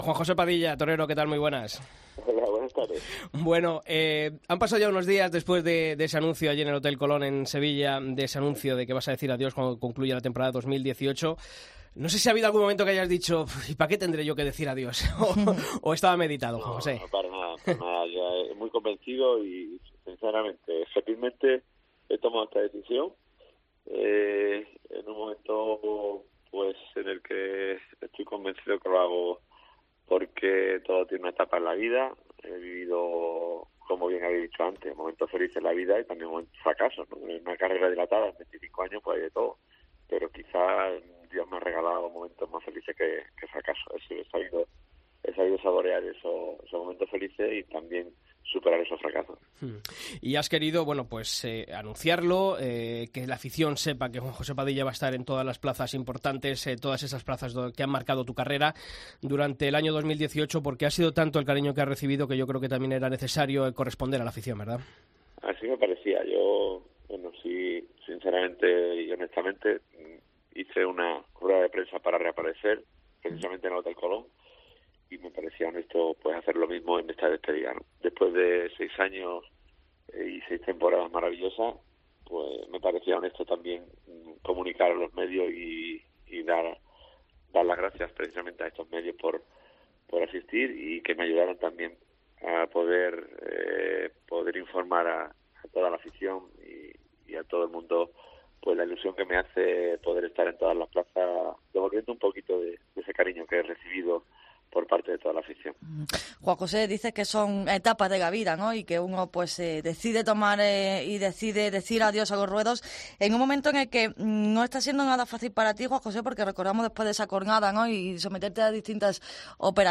Escucha la entrevista a Juan José Padilla en El Albero